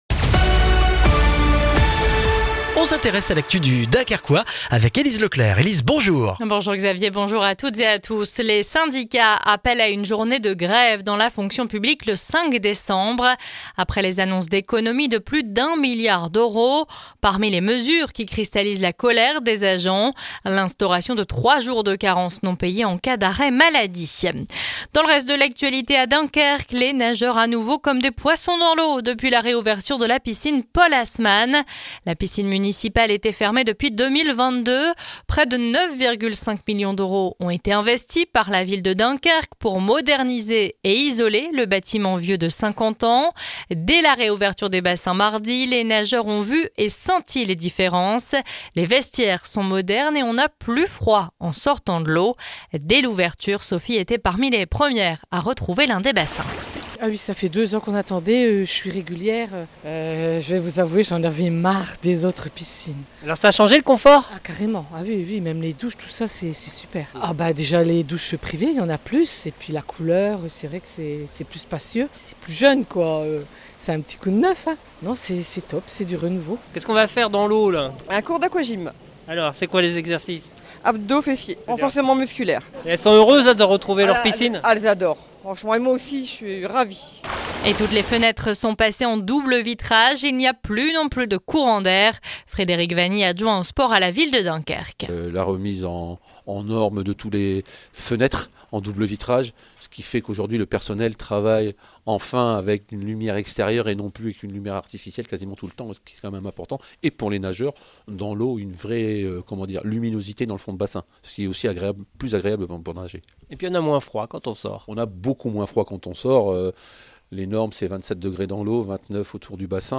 Le journal du vendredi 15 novembre dans le Dunkerquois